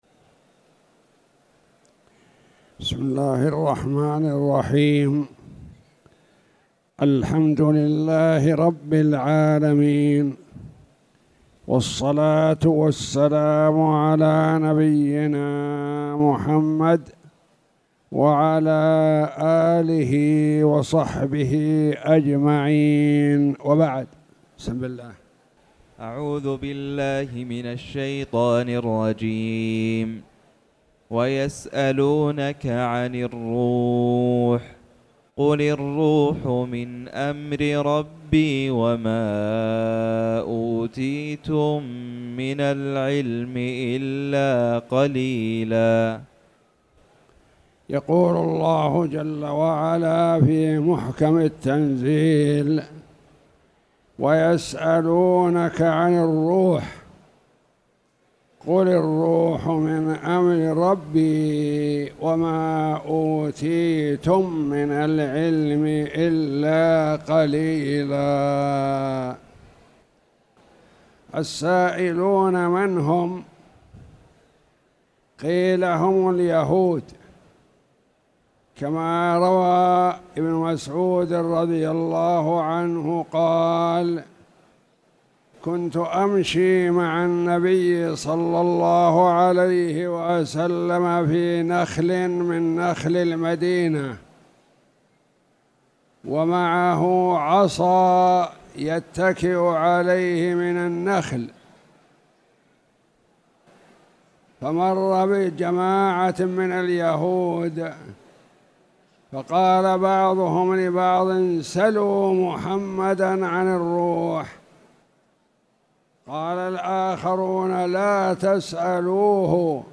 تاريخ النشر ٢٦ محرم ١٤٣٨ هـ المكان: المسجد الحرام الشيخ